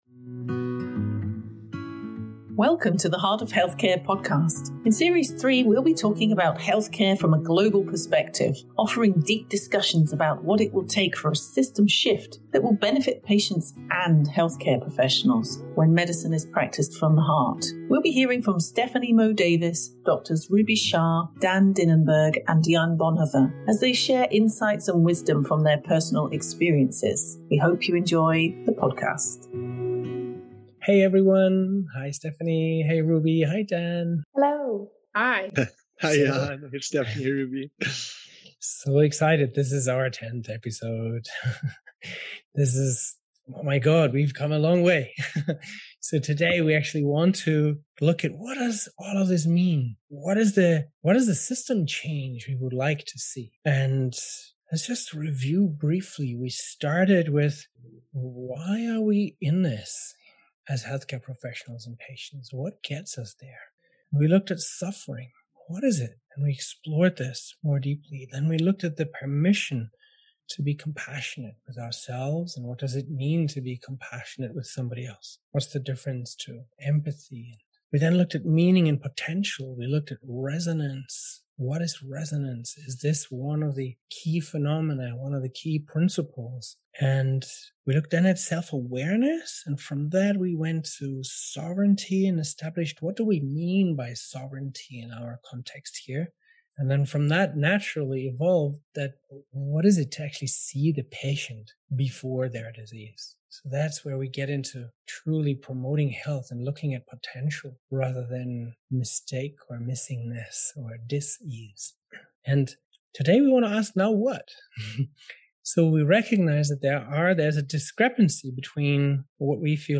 Following on from last week’s conversation, our panel of doctors and patients are keen to envisage the next step: how do we move forward?